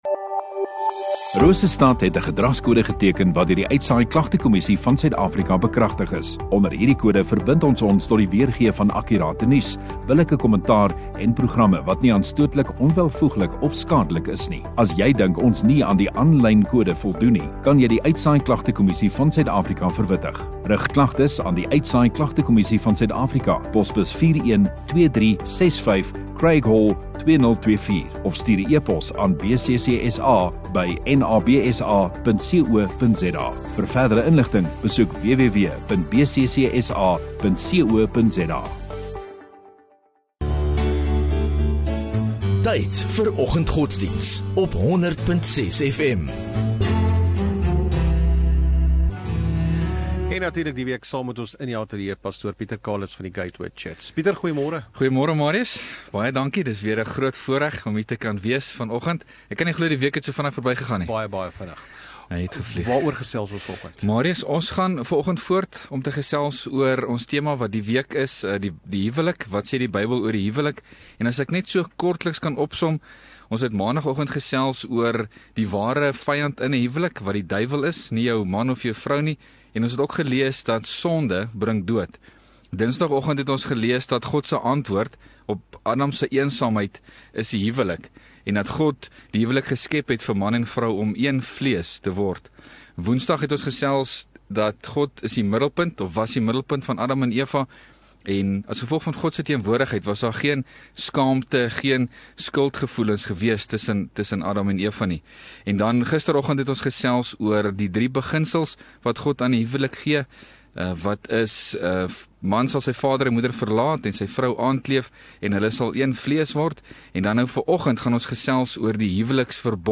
15 Nov Vrydag Oggenddiens